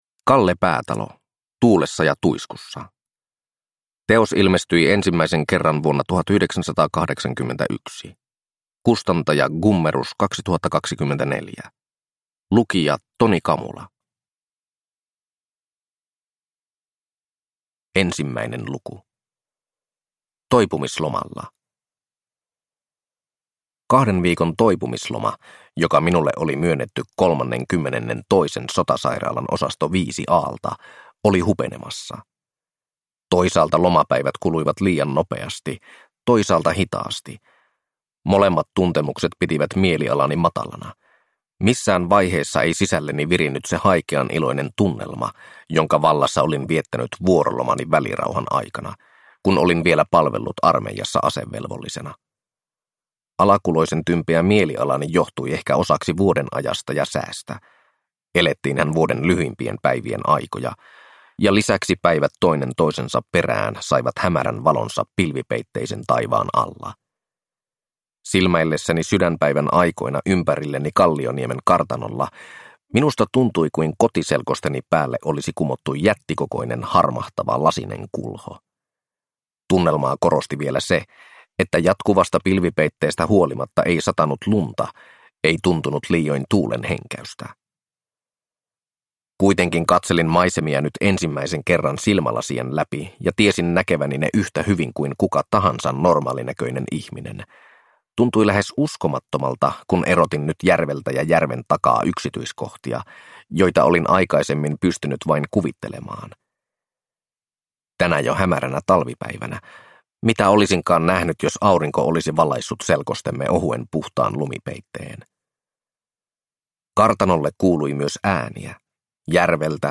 Tuulessa ja tuiskussa (ljudbok) av Kalle Päätalo